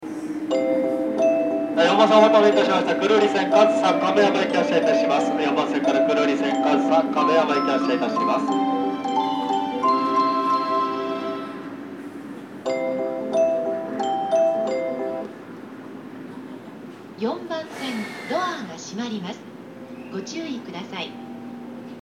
木更津駅　Kisarazu Station ◆スピーカー：BOSE
4番線発車メロディー